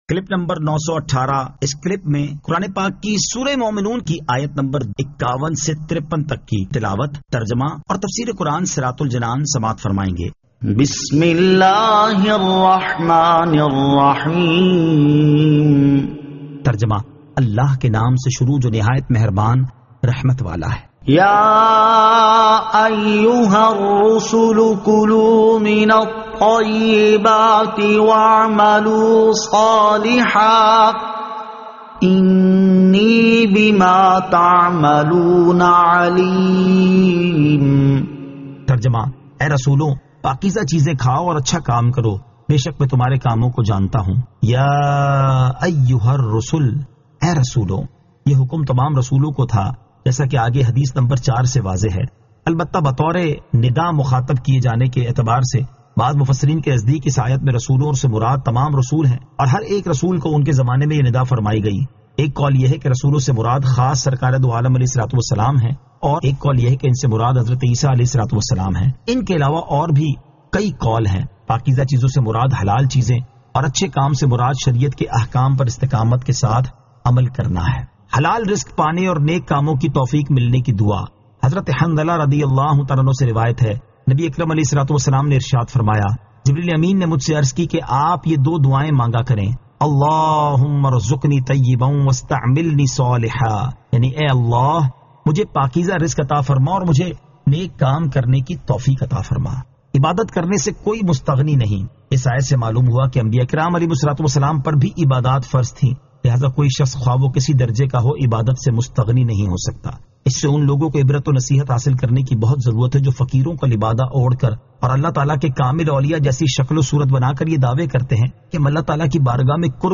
Surah Al-Mu'minun 51 To 53 Tilawat , Tarjama , Tafseer